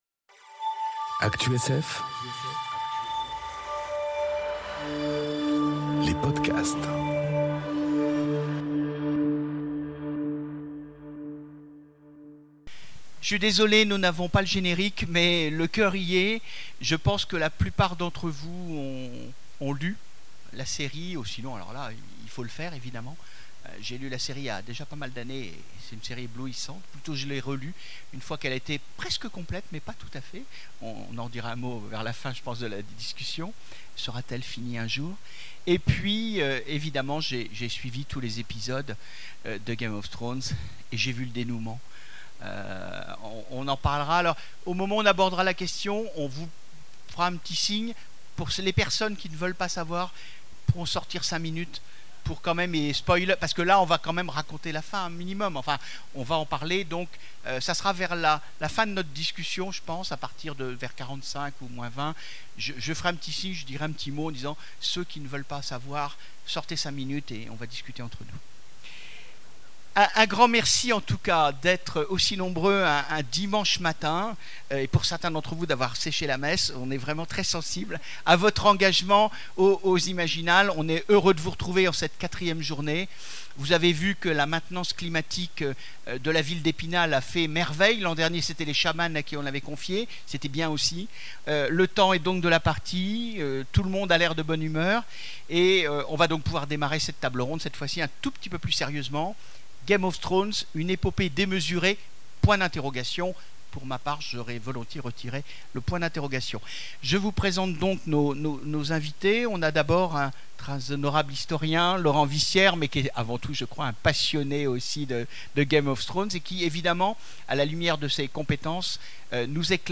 On est super désolés, elle n'est pas complète, il nous manque la fin.